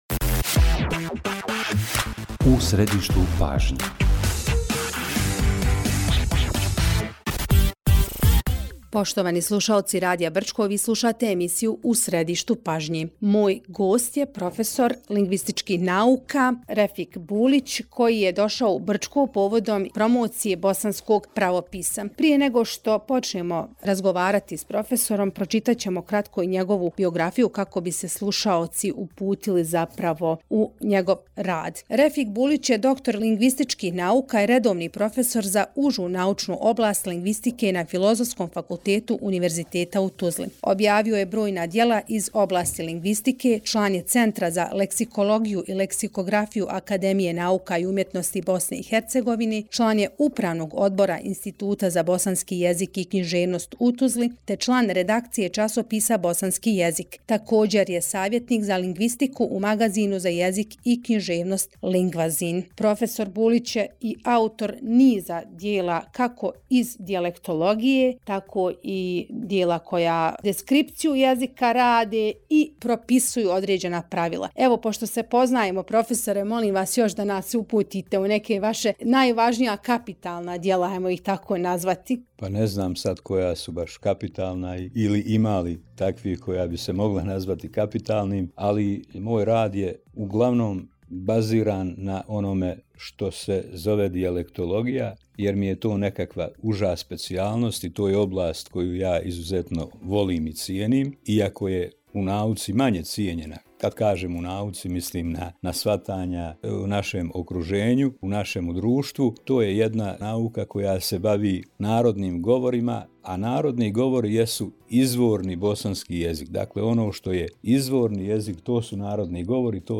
razgovor